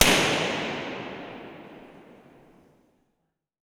Impulse response of cathedral in Busetto, Italy